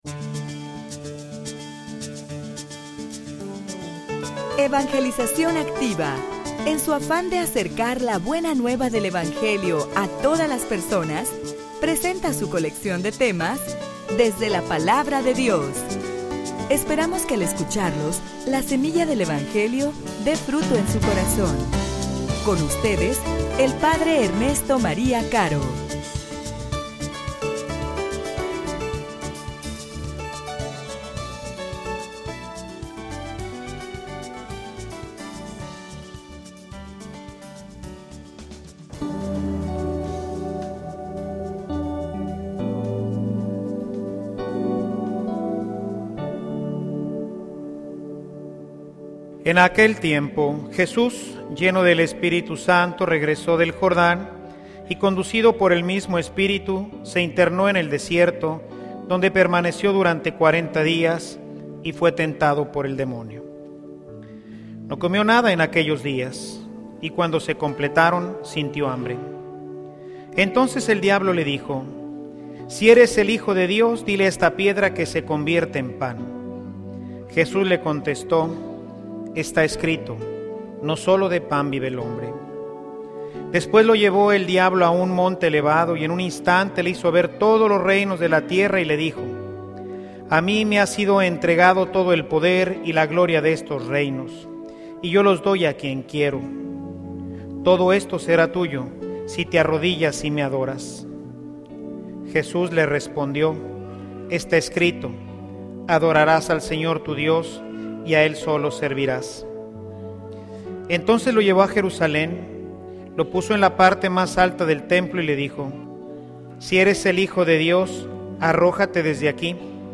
homilia_Preparandonos_para_la_batalla.mp3